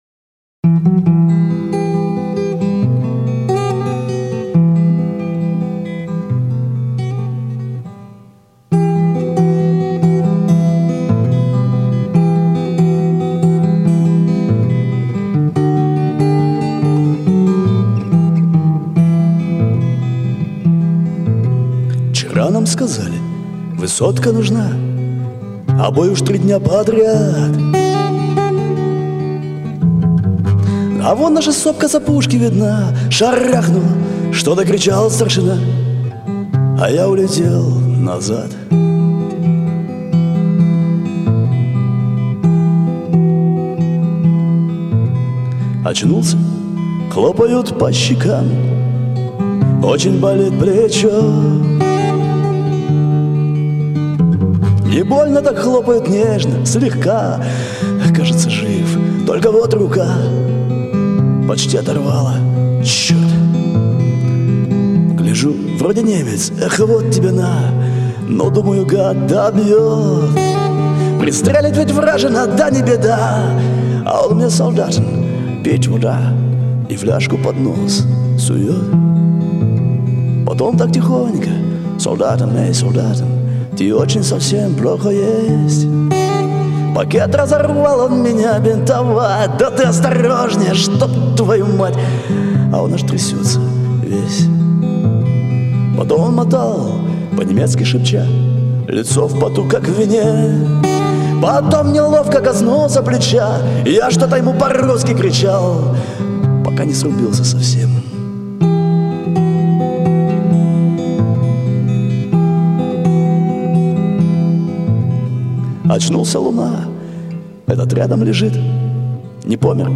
/ песни великой отечественной под гитару/